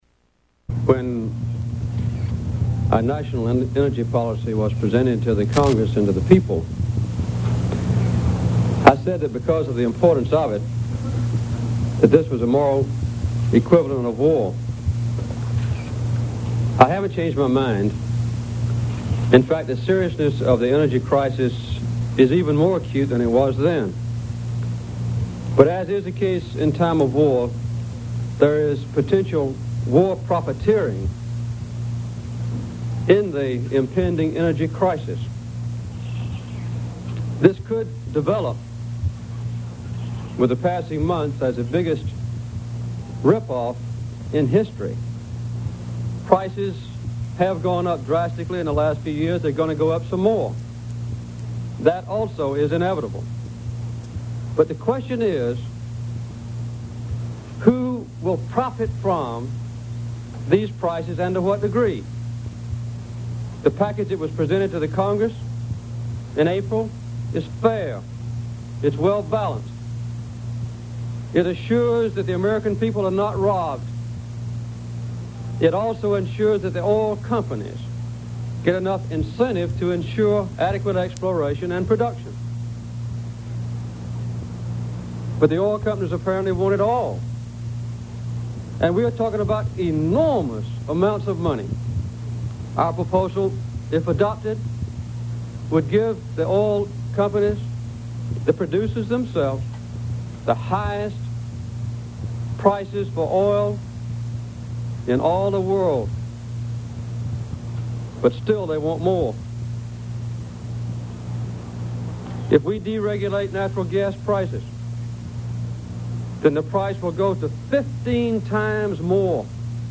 Press conference : segment